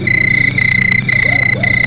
Crickets
CRICKETS.wav